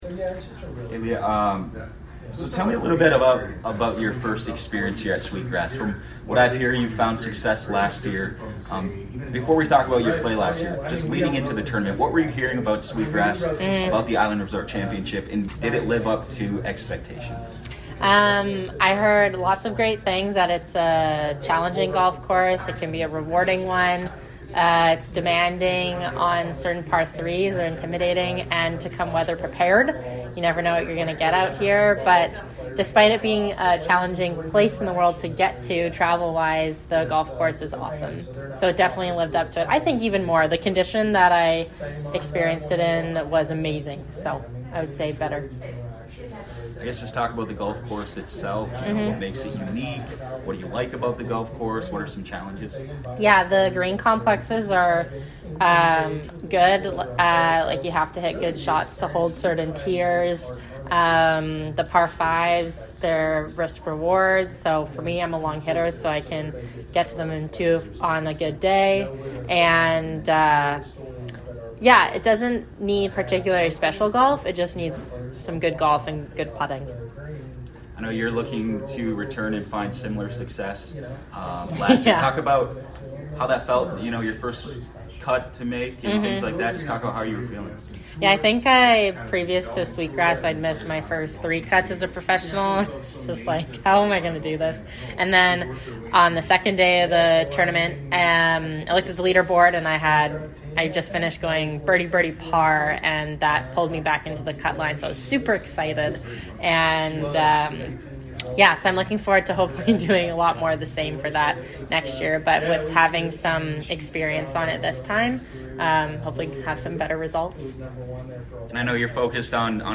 MEDIA SCRUM